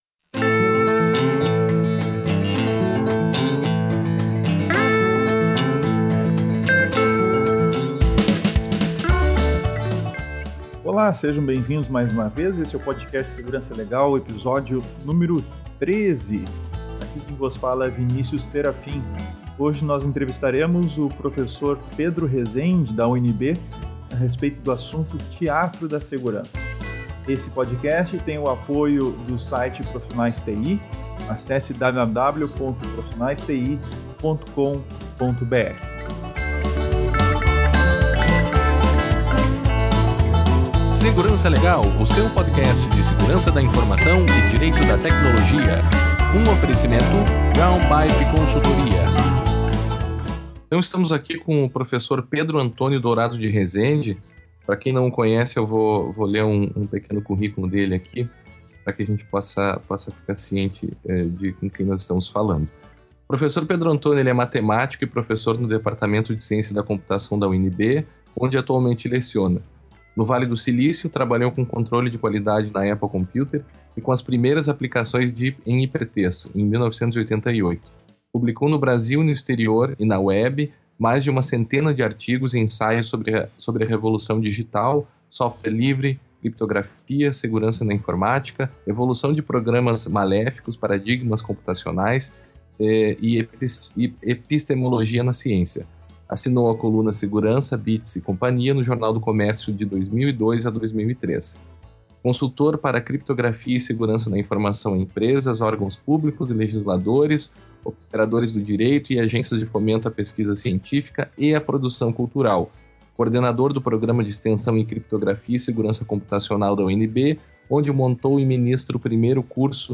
Entrevista: Teatro da Segurança